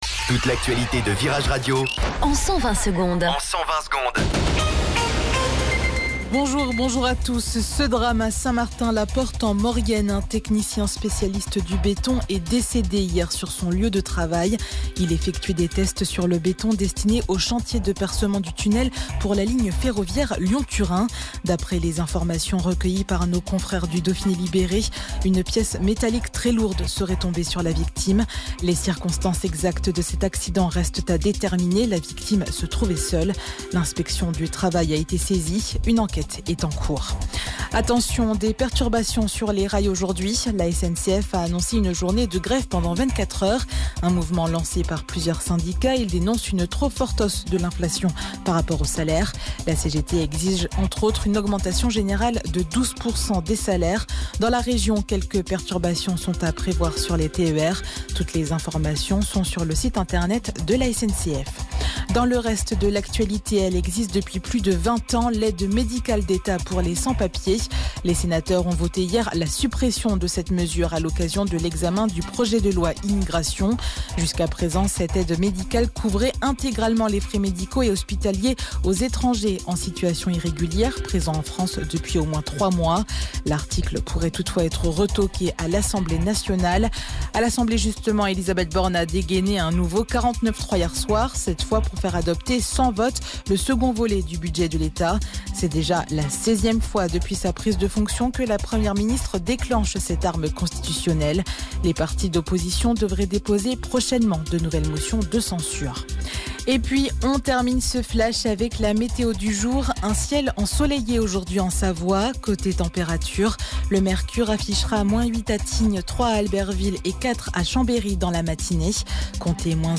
Flash Info Chambéry 08 Novembre 2023 Du 08/11/2023 à 07h10 Flash Info Télécharger le podcast Partager : À découvrir The Strokes : Un nouvel album pour dynamiter 2026, REALITY AWAITS !